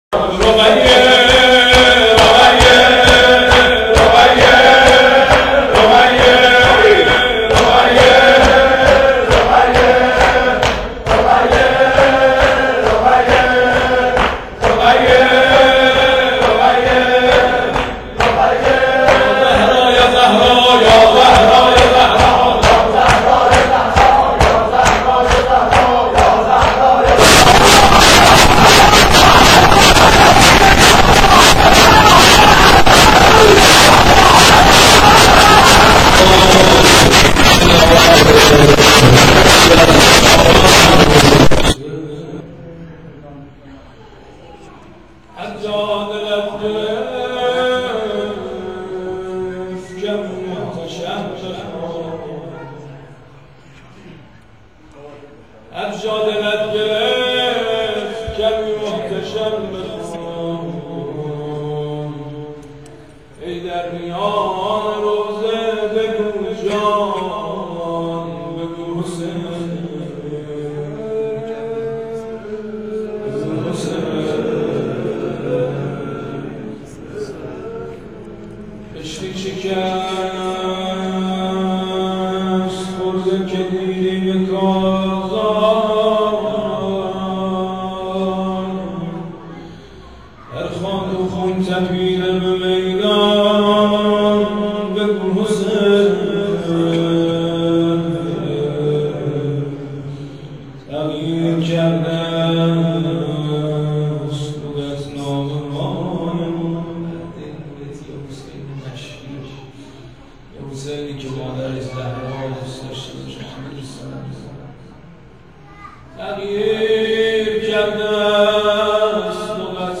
شور و روضه پایانی شب سوم.wma
شور-و-روضه-پایانی-شب-سوم.wma